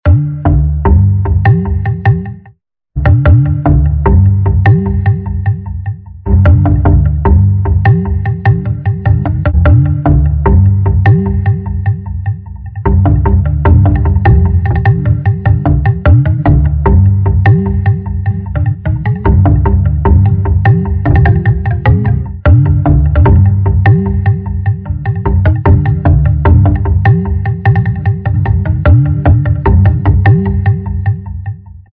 Dzwonek_3.mp3